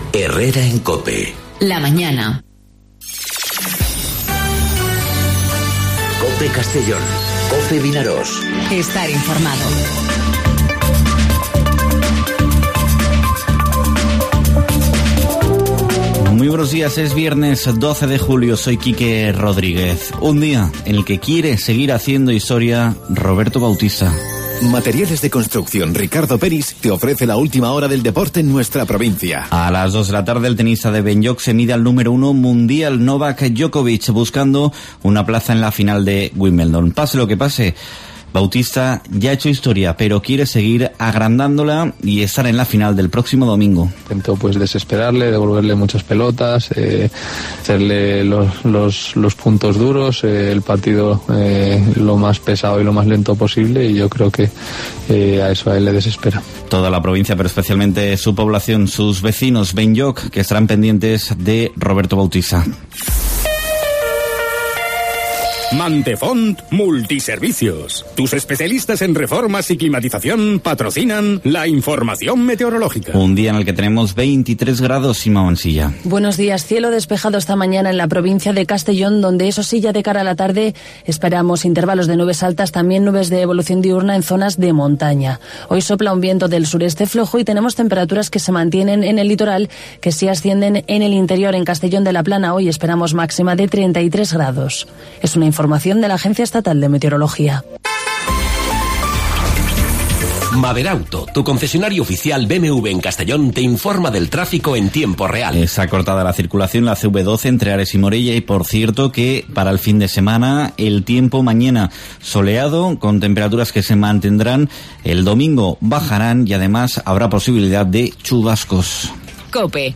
Informativo 'Herrera en COPE' Castellón (12/07/2019)